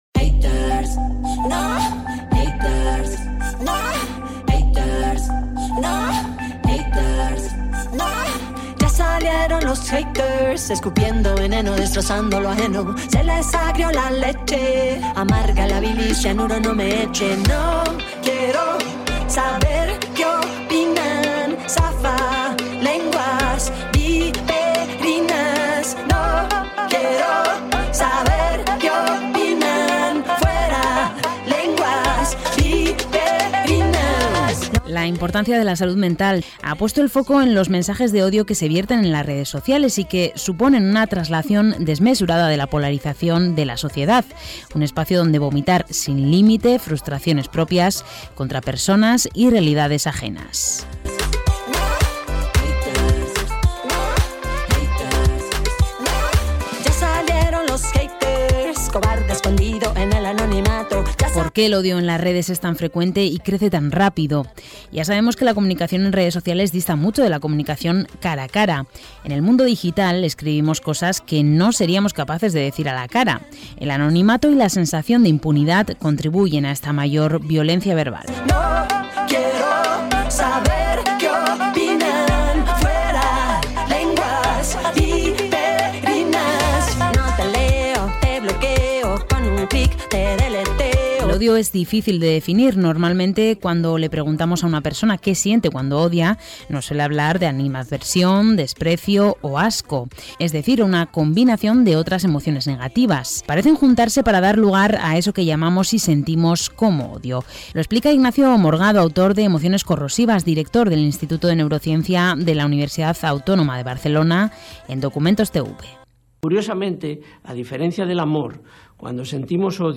Un reportaje sobre cómo el anonimato, la polarización y la falta de control en las plataformas disparan el acoso online